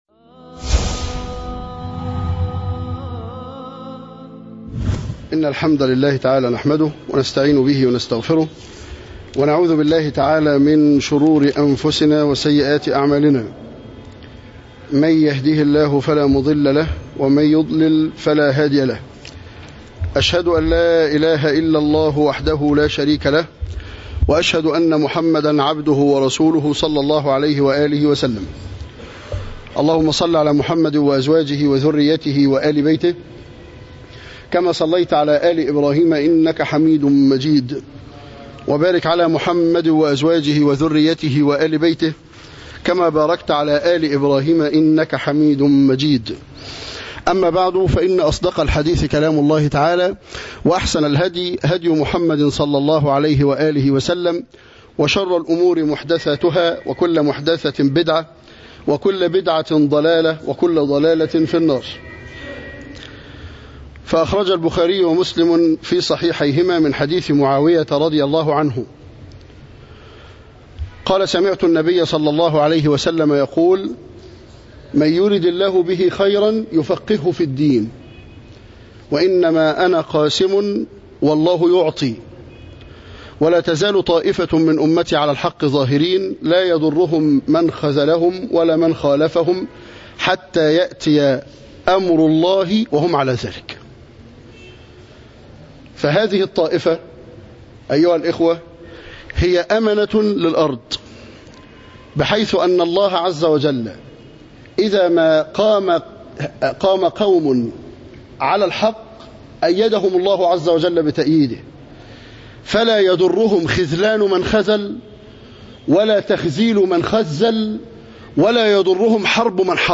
ندوة عهر وطهر
مسجد حمزة بن عبد المطلب - عين شمس